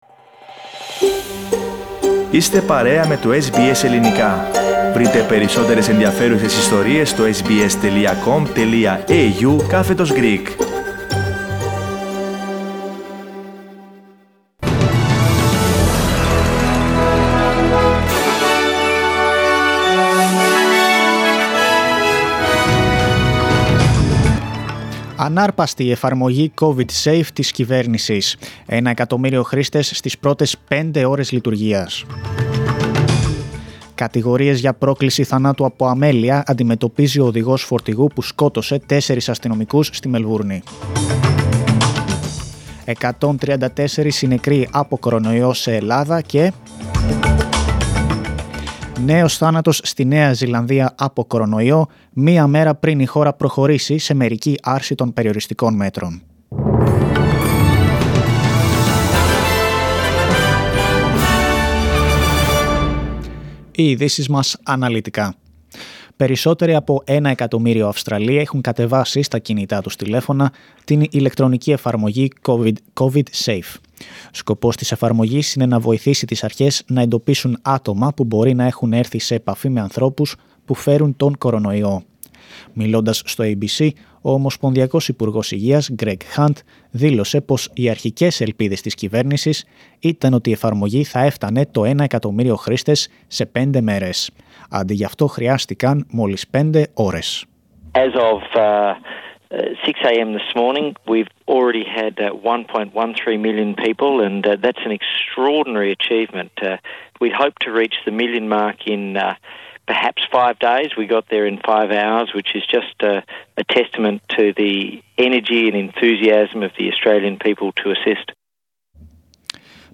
News from Australia, Greece, Cyprus and the world, in the news bulletin of Monday 27 of April.